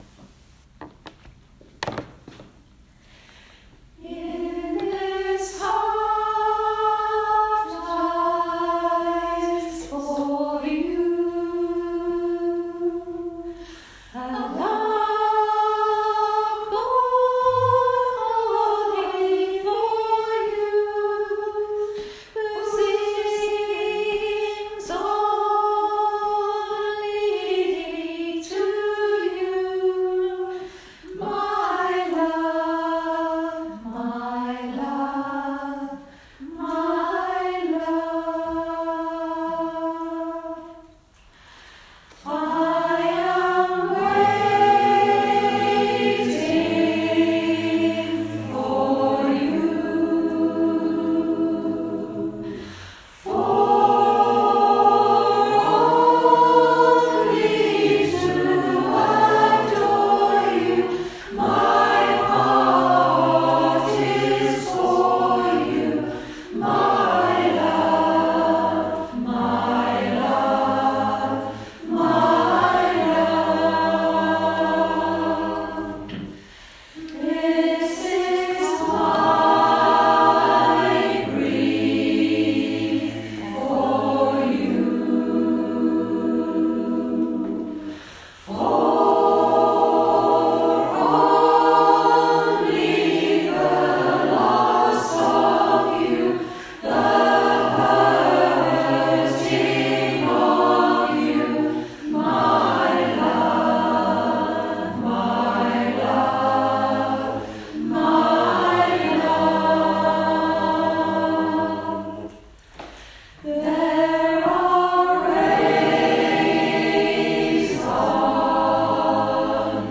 If they sound a little rough in places, please remember that they are intended as reminders of how the songs go and are a celebration of having managed to get through the whole song, rather than finished performances!